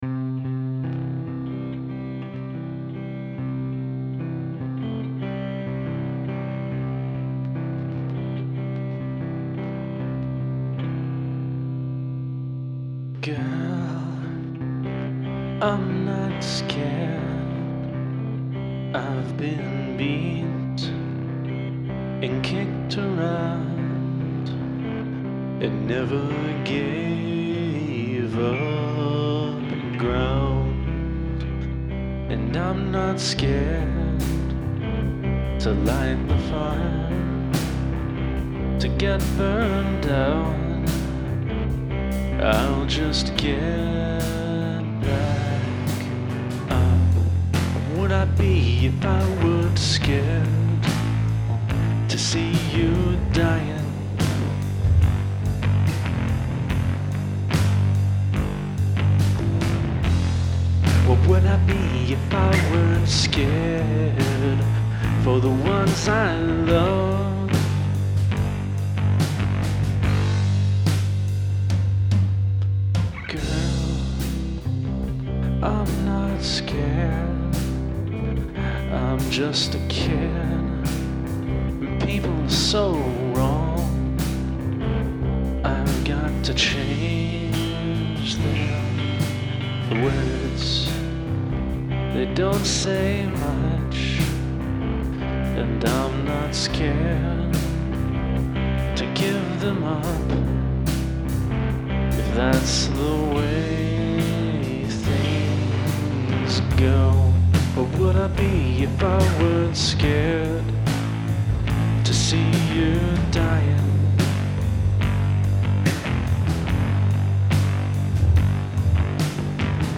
He's everything that's not a guitar or me singing.